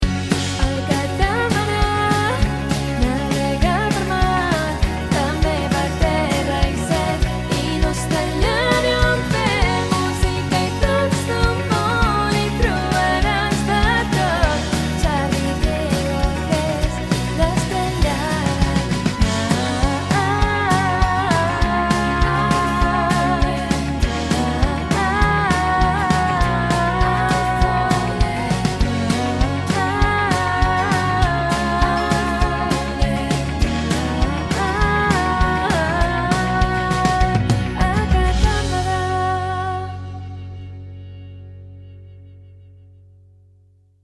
Indicatiu cantant del programa